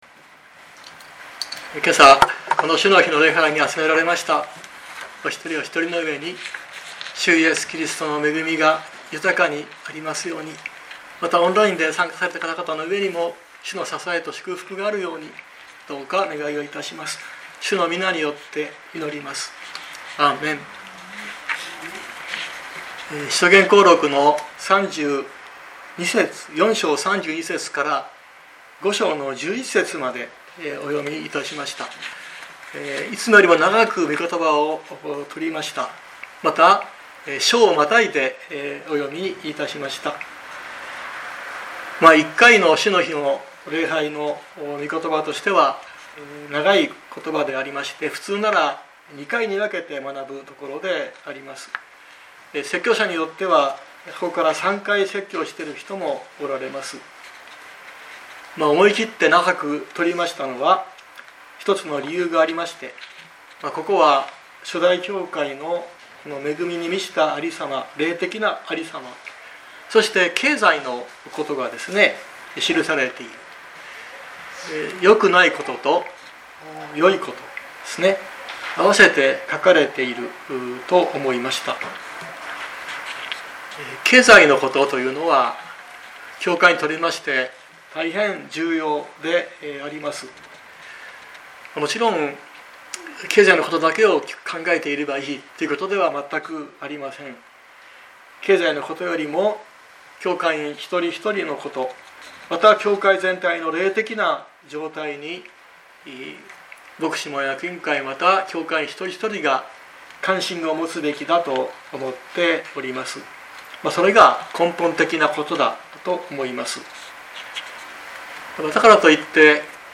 2024年10月13日朝の礼拝「神の憐みと裁き」熊本教会
説教アーカイブ。